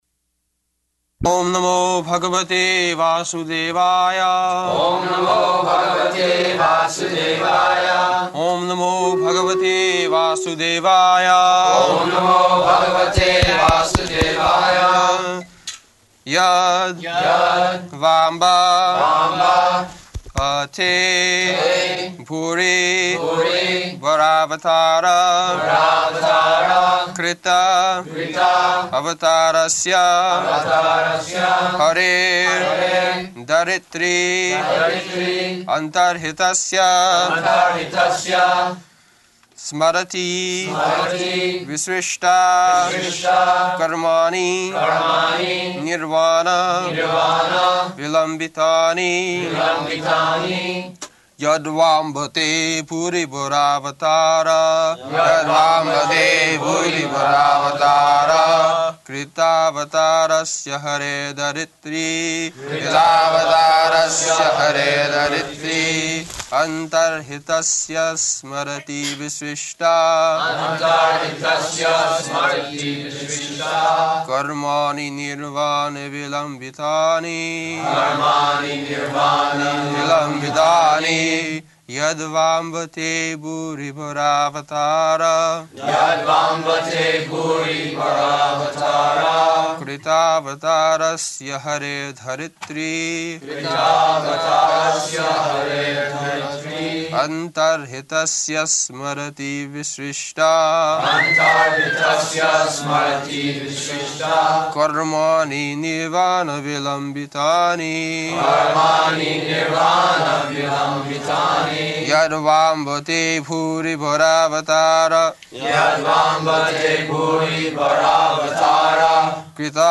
January 19th 1974 Location: Honolulu Audio file
[devotees repeat] [leads chanting of verse, etc.]